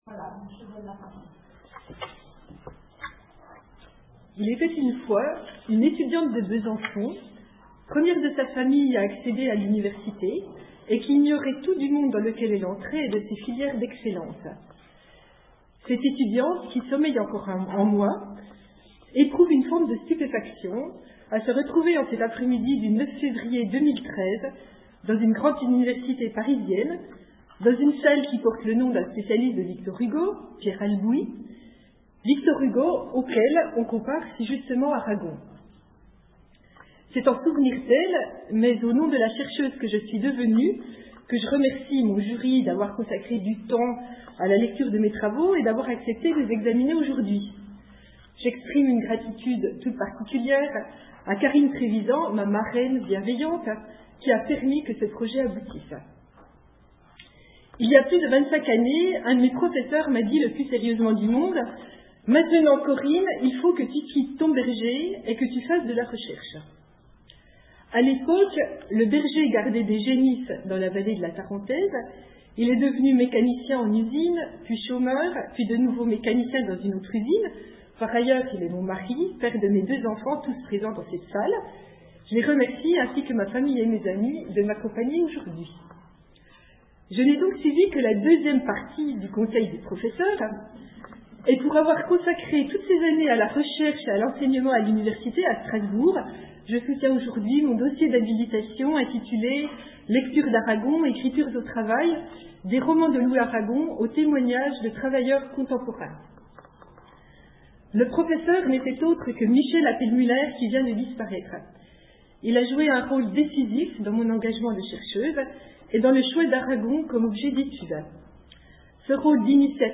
discours de soutenance d’HDR, 9 février 2013